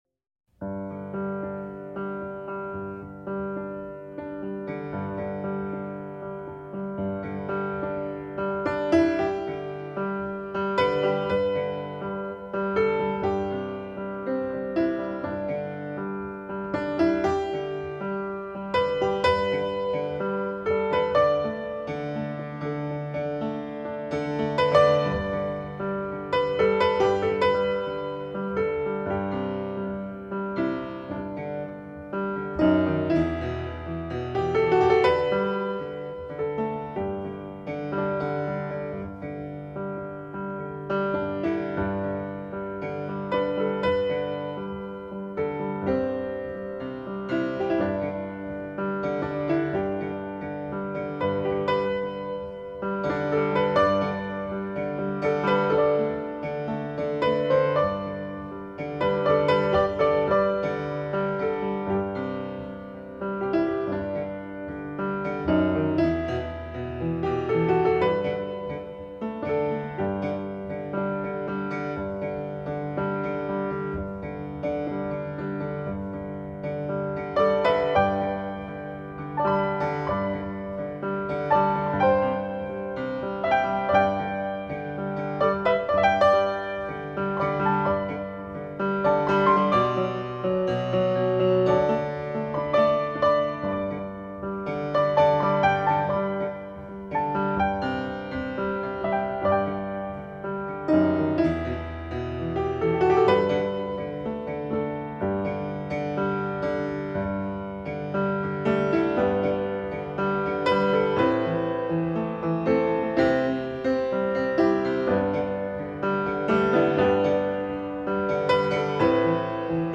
hymns
piano solo